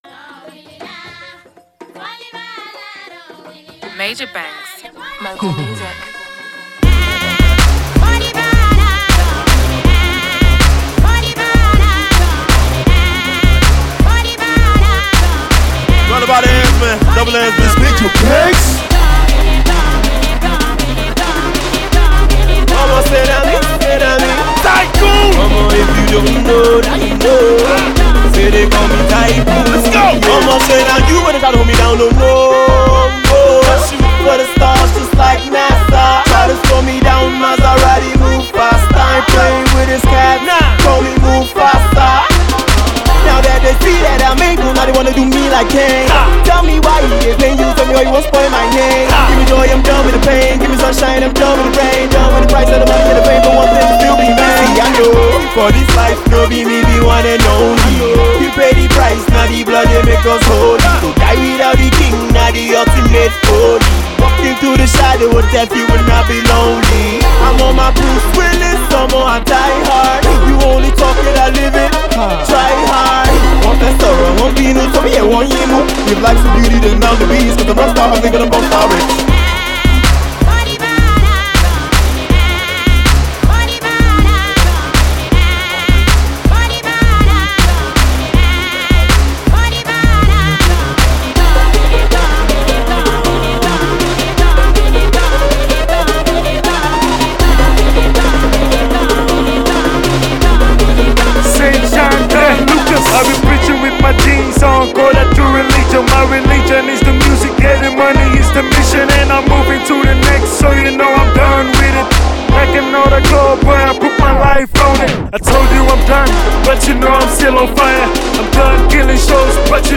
The Rick Ross-reminiscent rapper
drops some hefty bars on his new joint